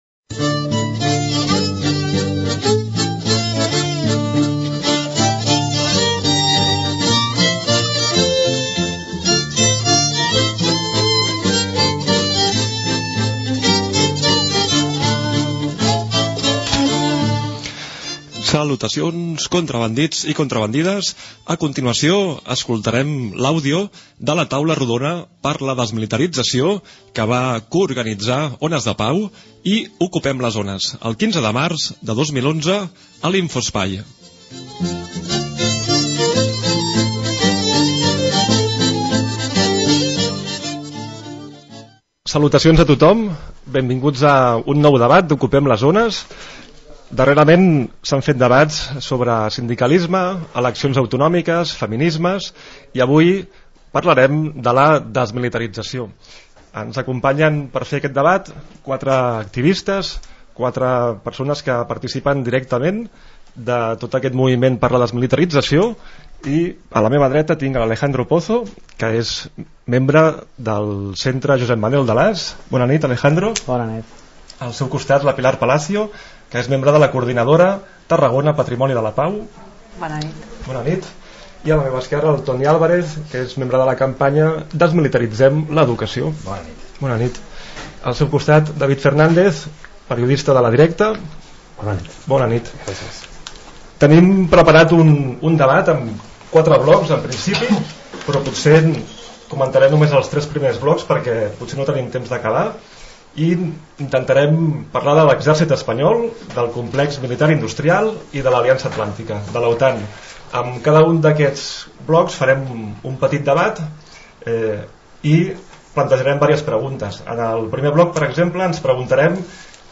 Presentació i fragment del debat “Desmilitarització”, gravat el 15 de març de 2011 a l’Infoespai de la Plaça del Sol de Gràcia (Barcelona), organitzat per Ones de Pau i Okupem les Ones